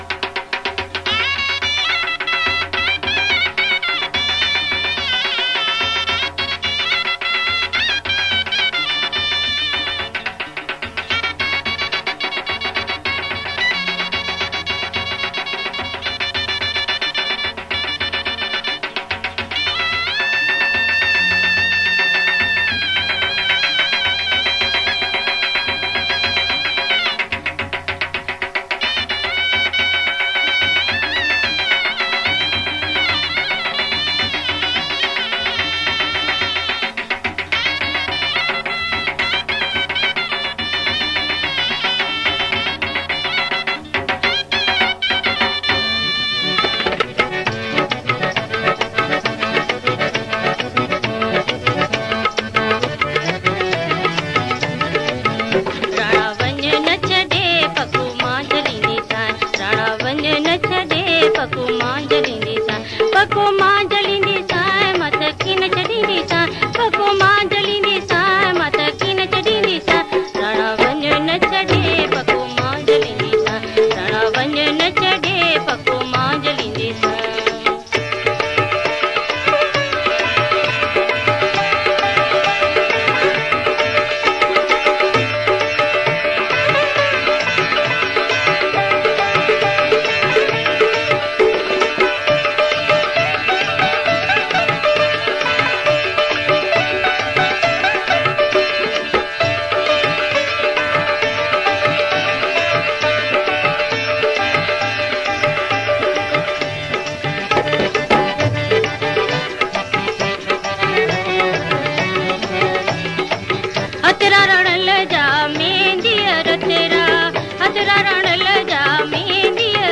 Sindhi Ladas and Marriage Mazaki Songs